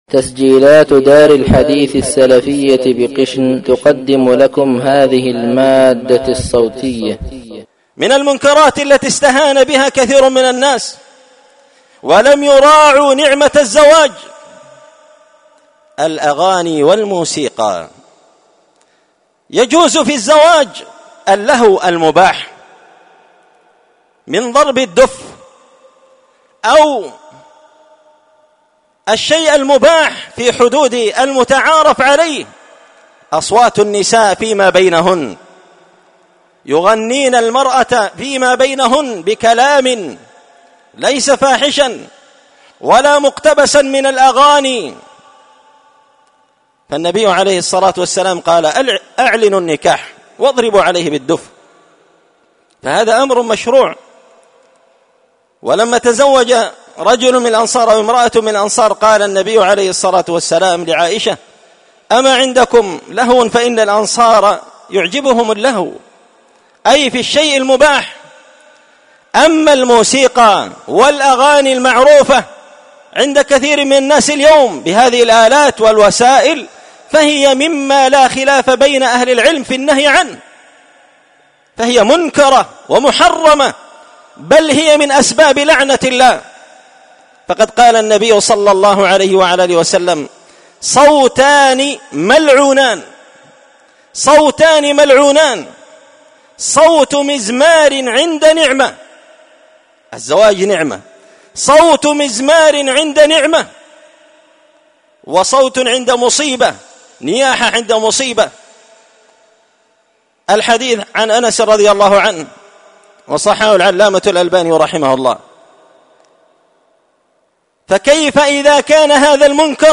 مقتطف من خطبة جمعة تذكير الناس بخطر الاستهانة بمنكرات الأعراس
دار الحديث بمسجد الفرقان ـ قشن ـ المهرة ـ اليمن